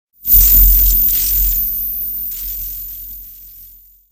Electrifying Texture, Electric Shock 2 Sound Effect Download | Gfx Sounds
Electrifying-texture-electric-shock-2.mp3